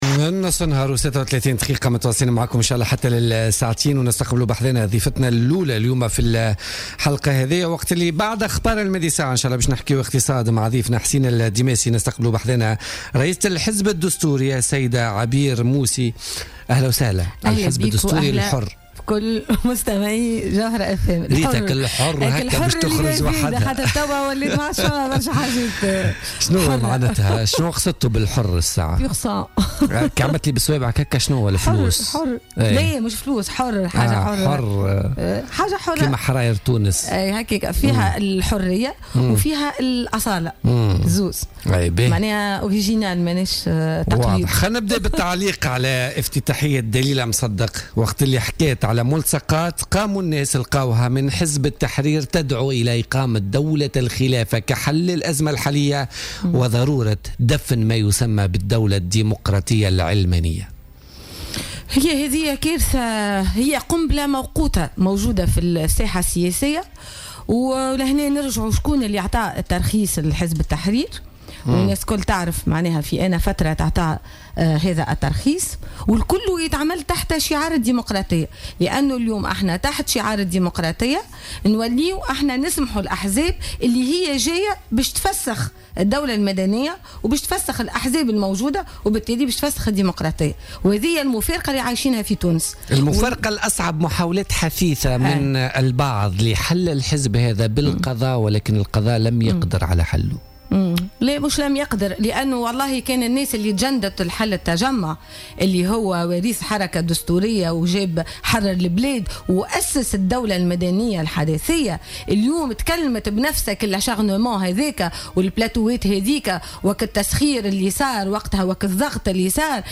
أكدت رئيسة الحزب الدستوري الحرّ عبير موسي ضيفة بولتيكا اليوم الجمعة 17 مارس 2017 أن حزب التحرير هو كارثة وقنبلة موقوتة موجودة في الساحة السياسية مشيرة إلى أن الترخيص لهذا الحزب معروف في أي فترة تم ومن أسند الترخيص له .